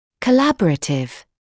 collaborative.mp3